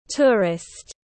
Du khách tiếng anh gọi là tourist, phiên âm tiếng anh đọc là /ˈtʊə.rɪst/.
Tourist /ˈtʊə.rɪst/